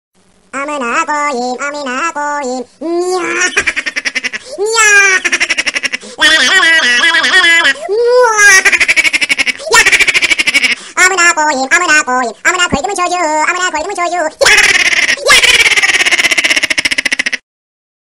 Fluffy Laughs Sound
meme